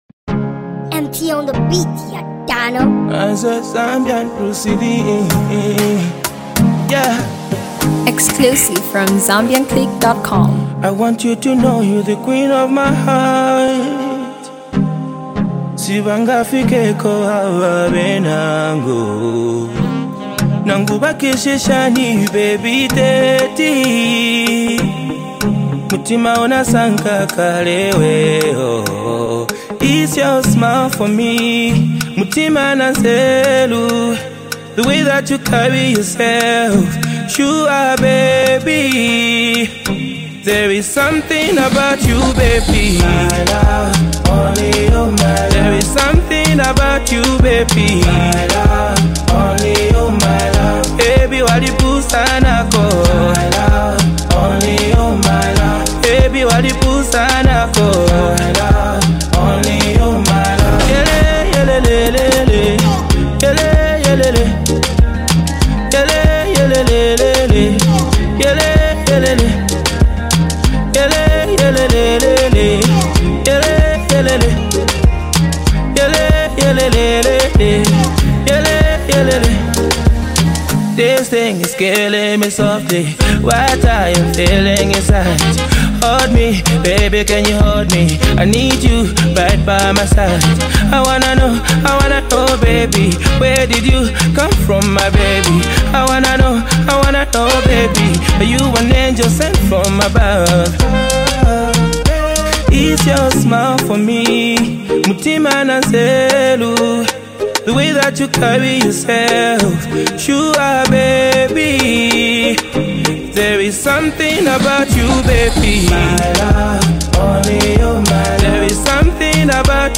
love jam